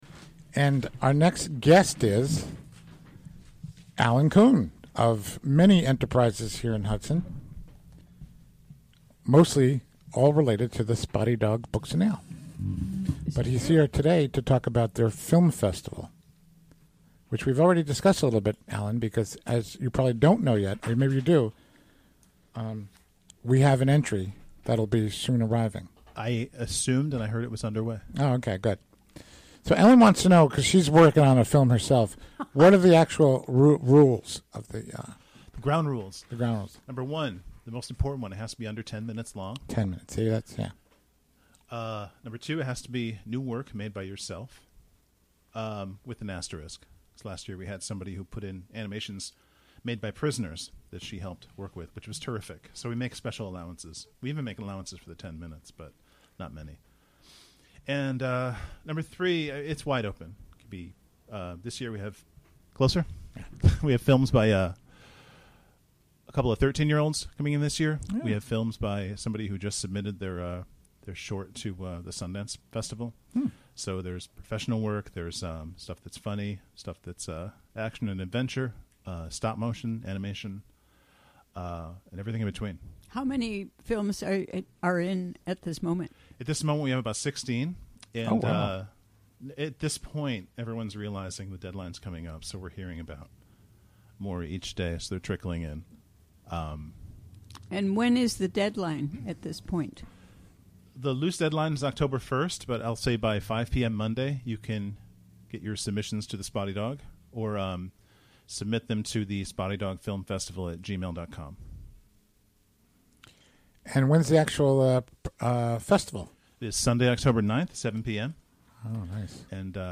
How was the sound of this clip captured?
Recorded during the WGXC Afternoon Show Thursday, September 29, 2016.